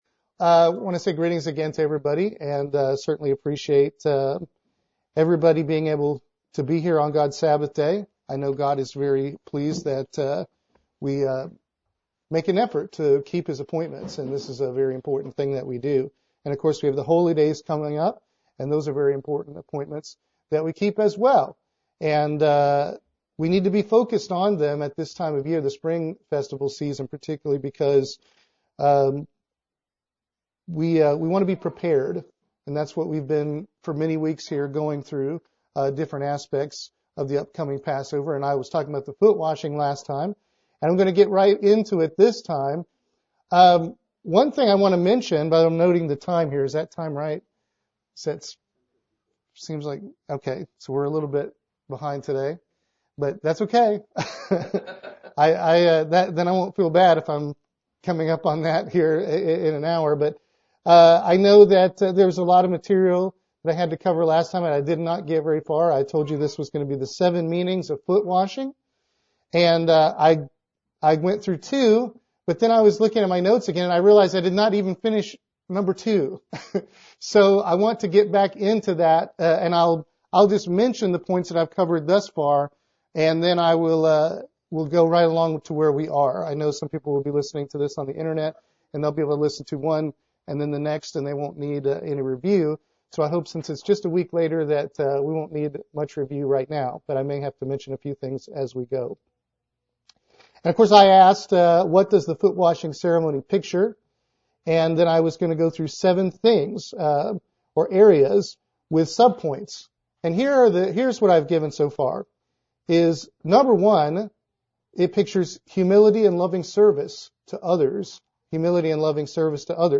The second sermon in the series explaining the tremendously meaningful foot-washing ceremony at the Last Supper.